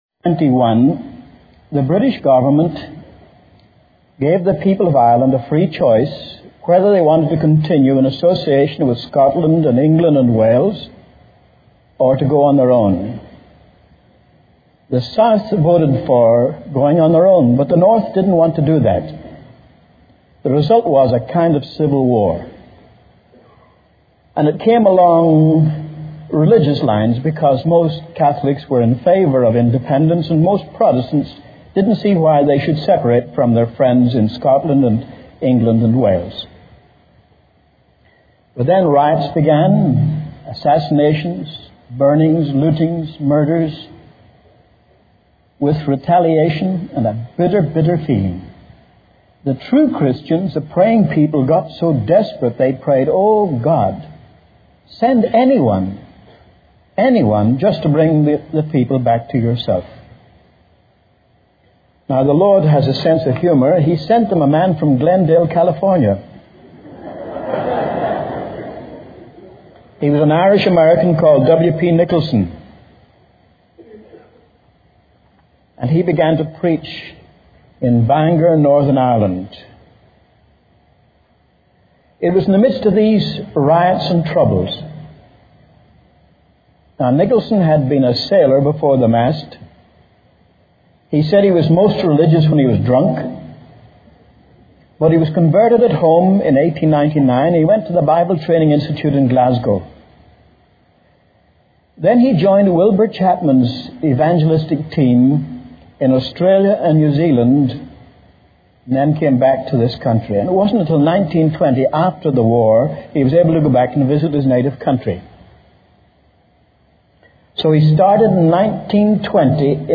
This sermon recounts historical revivals and movements of God in various countries, highlighting the impact of prayer, powerful preaching, and the work of true Christians in bringing about spiritual awakenings.